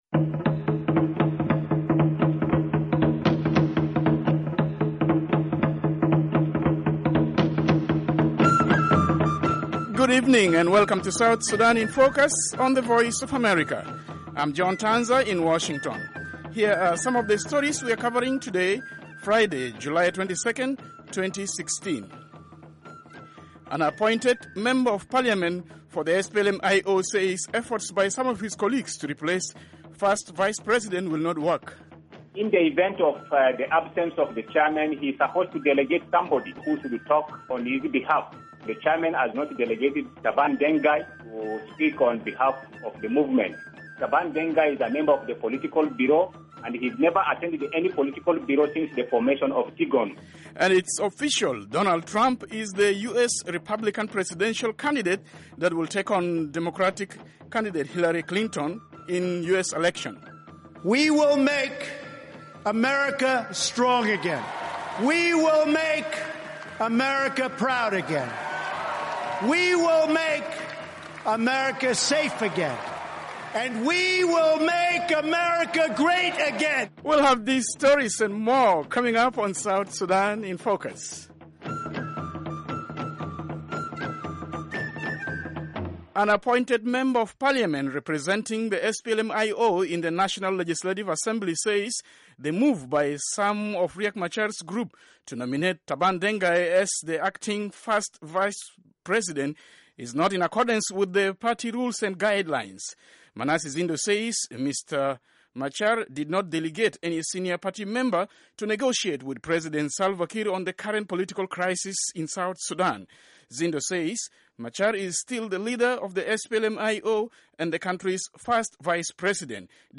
South Sudan in Focus is a 30-minute weekday English-language broadcast/internet program covering rapidly changing developments in the new nation of South Sudan and the region.